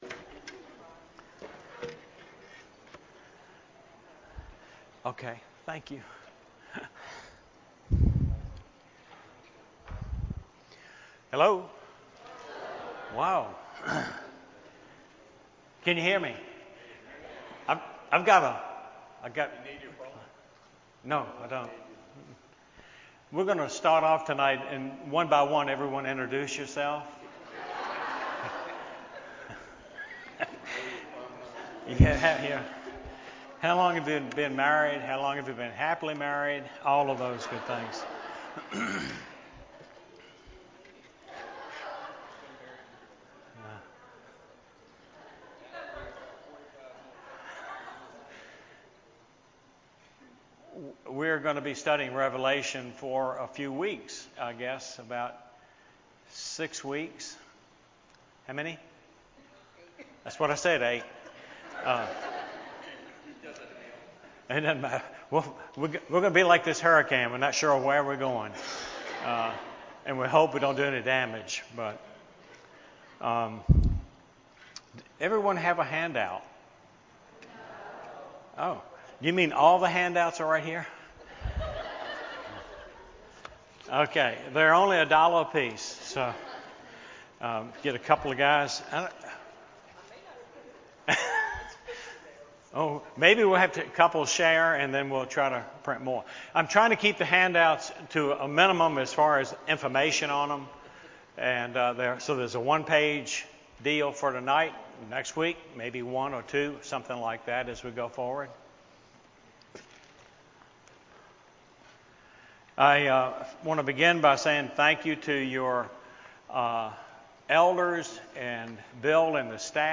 Wednesday Night Revelation Study Week 1